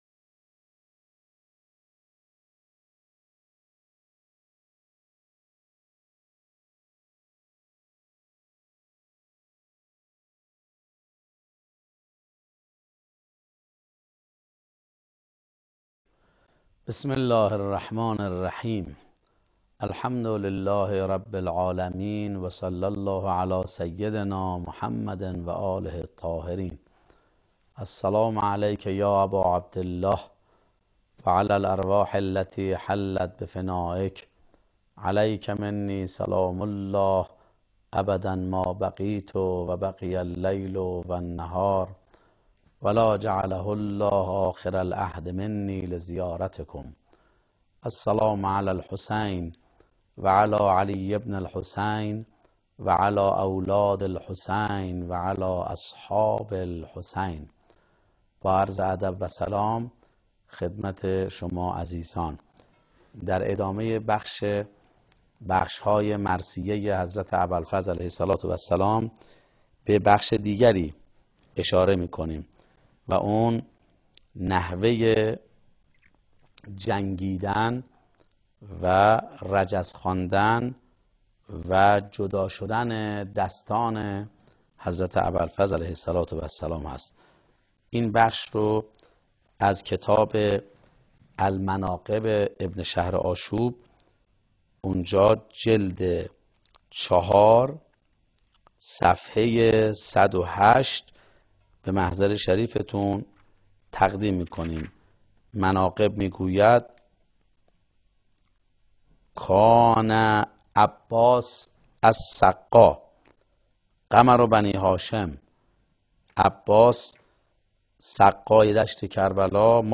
سلسله گفتارهایی پیرامون تبارشناسی عاشورا